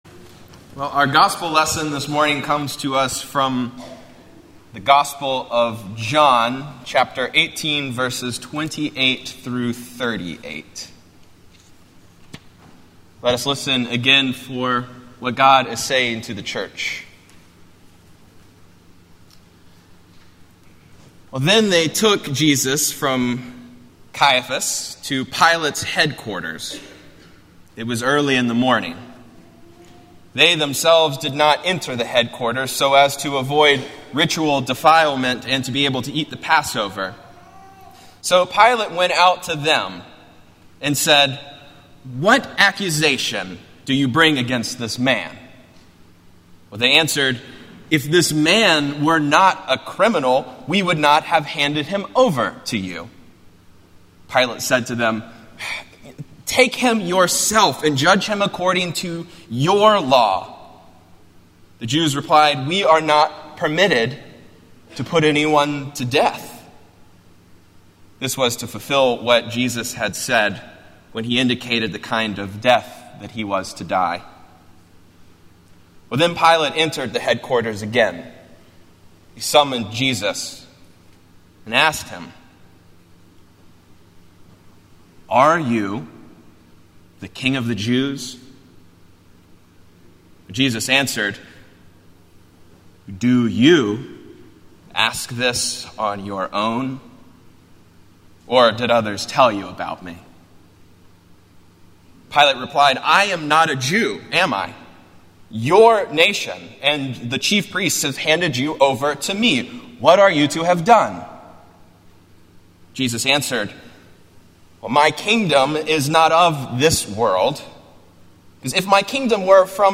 Morningside Presbyterian Church - Atlanta, GA: Sermons: So Are You a King?